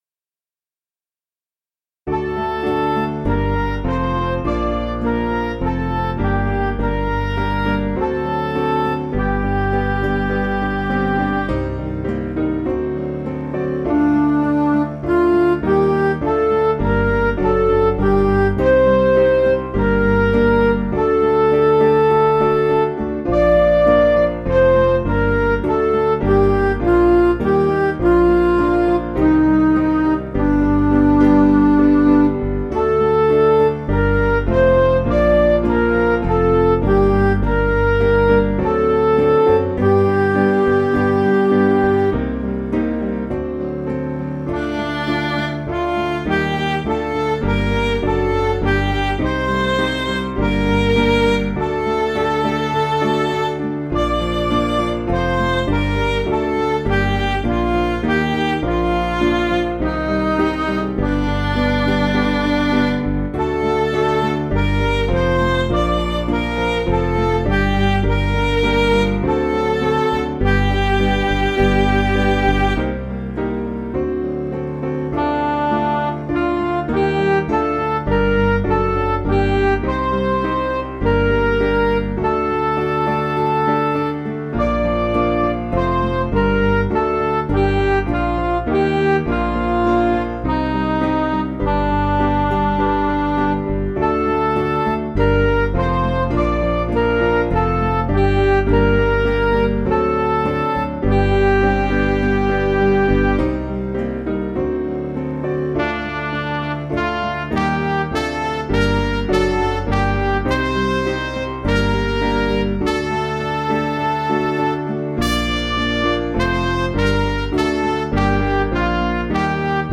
Piano & Instrumental
(CM)   4/Gm